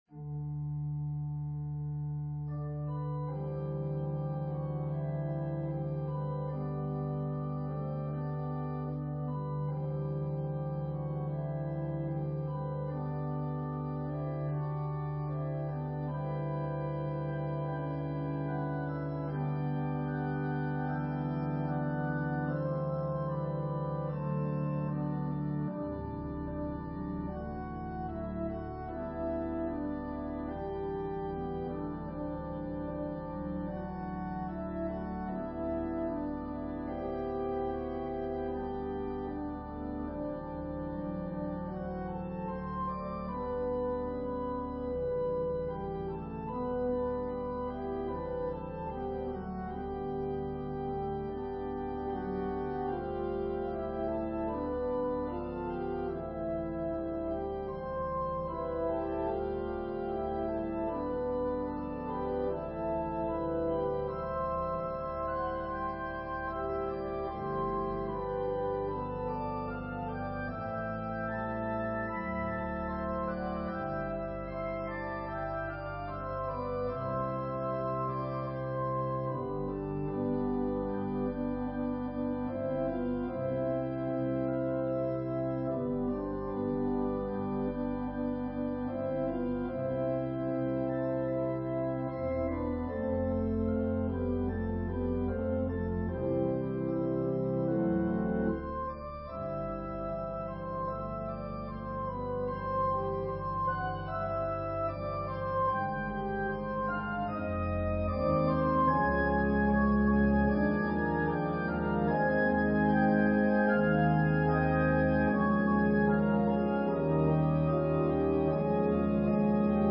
An organ solo arrangement
Voicing/Instrumentation: Organ/Organ Accompaniment